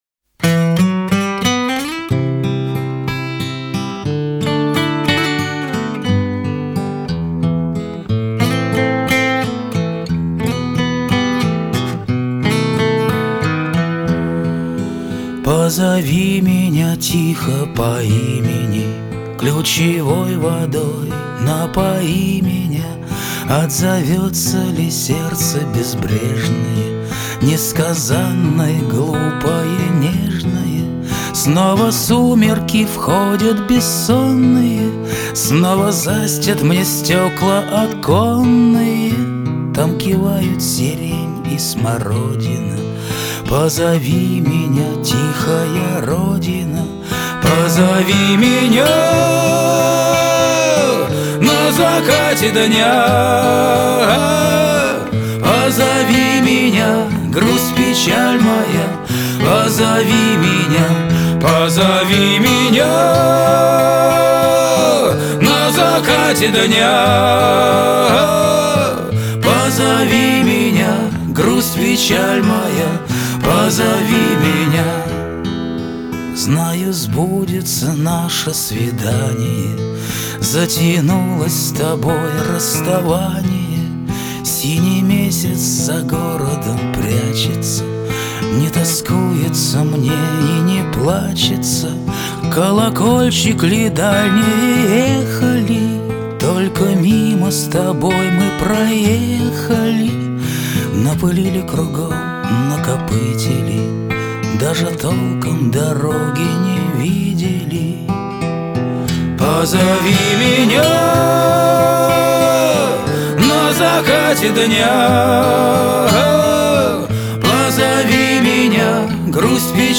Музыка в финале сериала